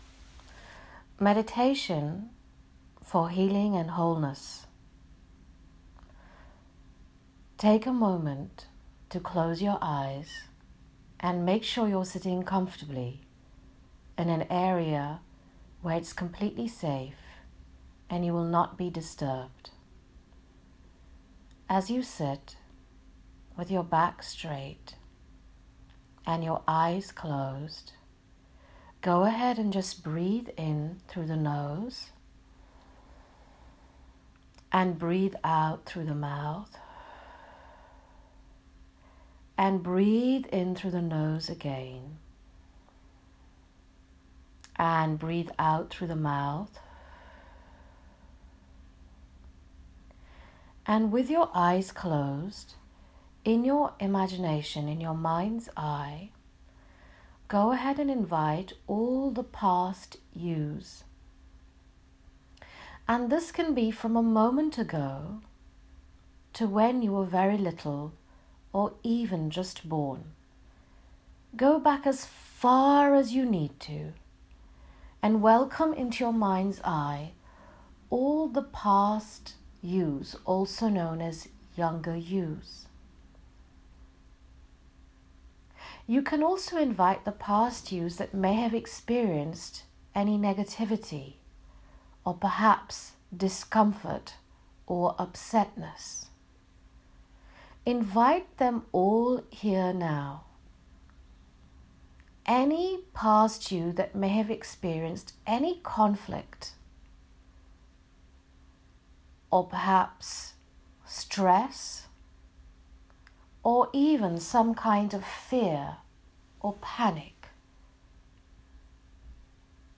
Here is the Healing Hugging Meditation again.